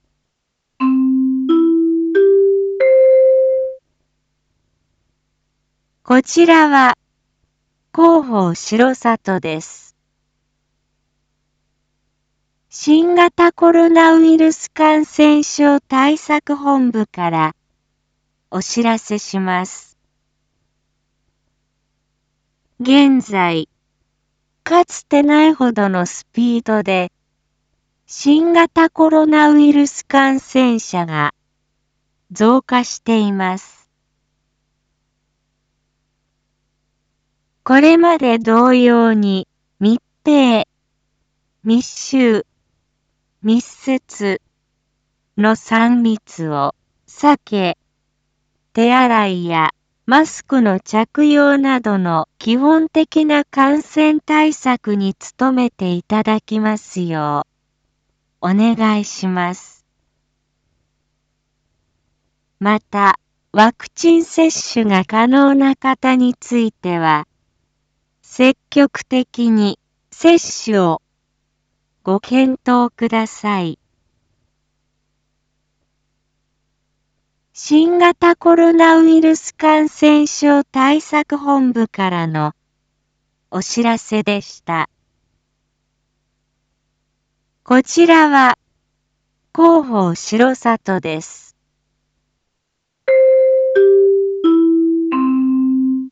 一般放送情報
Back Home 一般放送情報 音声放送 再生 一般放送情報 登録日時：2022-07-21 19:01:37 タイトル：4.7.21 19時放送分 インフォメーション：こちらは広報しろさとです。 新型コロナウイルス感染症対策本部からお知らせします。